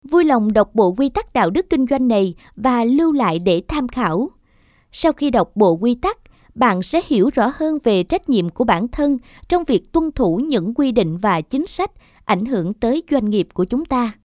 女越南1T-9 越南语女声 低沉|激情激昂|大气浑厚磁性|沉稳|娓娓道来|科技感|积极向上|神秘性感|素人